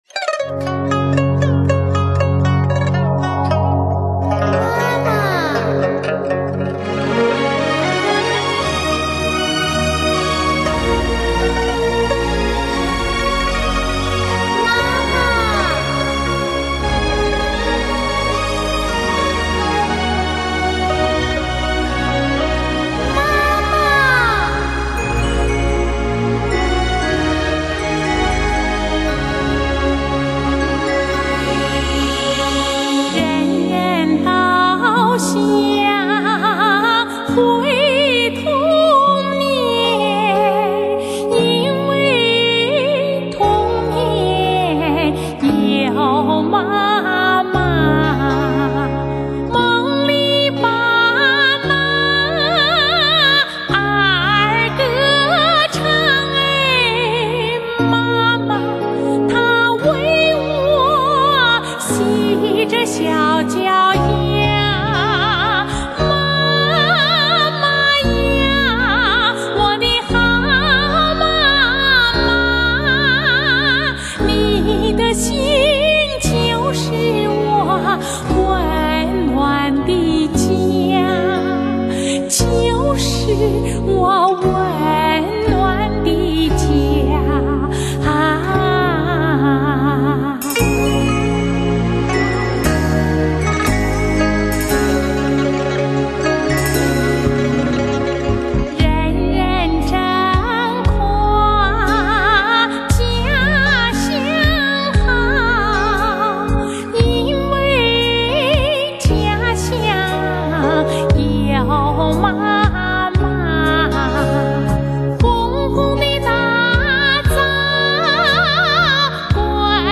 中国著名女高音歌唱家